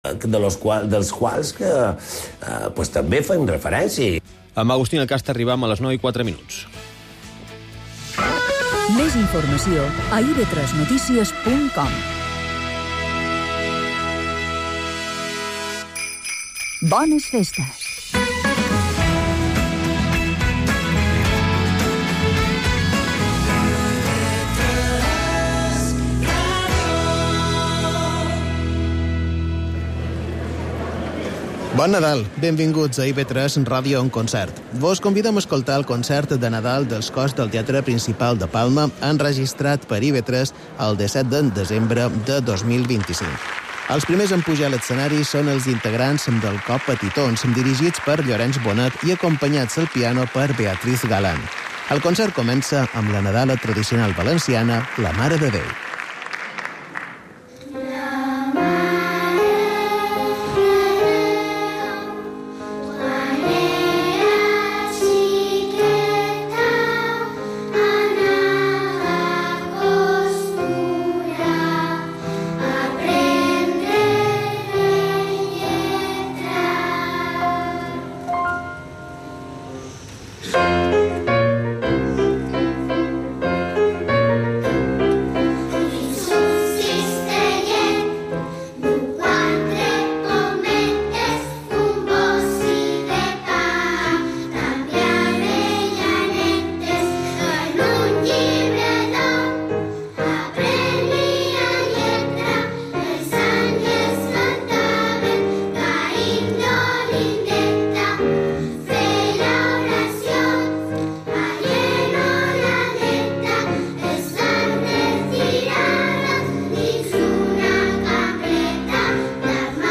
Emissió de concerts enregistrats per IB3 Ràdio.